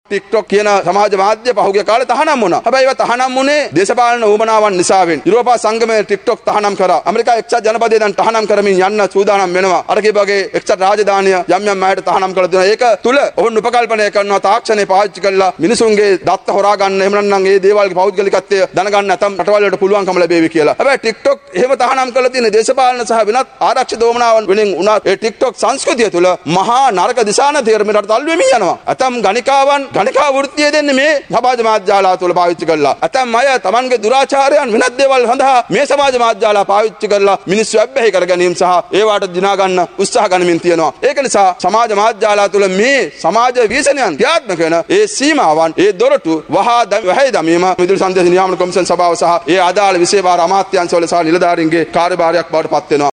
පාර්ලිමේන්තුවේදී ඊයේ පස්වරුවේයි ඔහු මේ බව කියා සිටියේ .